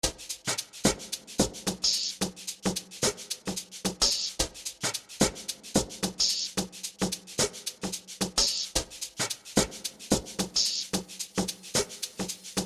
Amapiano music
is an infectious record that incoperates melodies
other piano elements